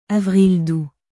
avril douxアヴリル ドゥ